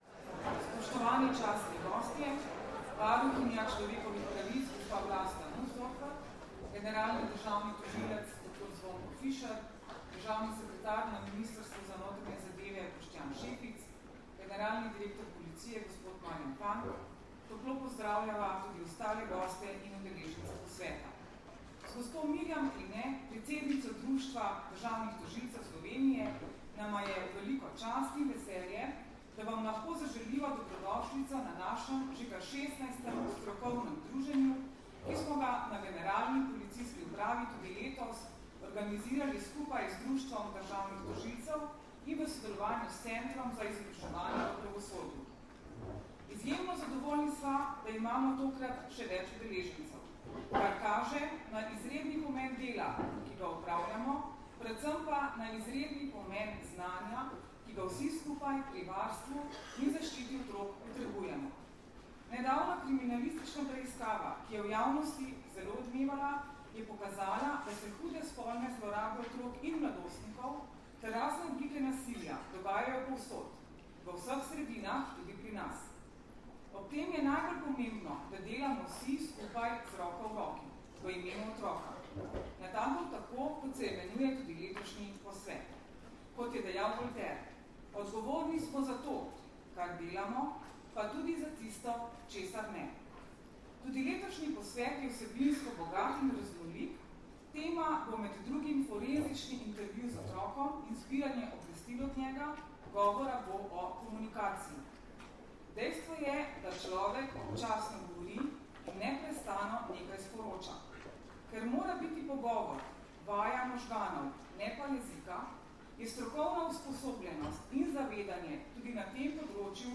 V Kongresnem centru Brdo, Predoslje, se je danes, 6. aprila 2017, pod naslovom V imenu otroka začel dvodnevni posvet na temo problematike poznavanja otroka za kvalitetnejšo obravnavo v postopkih, ki ga organizirata Policija in Društvo državnih tožilcev Slovenije v sodelovanju s Centrom za izobraževanje v pravosodju.
Zvočni posnetek pozdravnega nagovora mag. Tatjane Bobnar (mp3)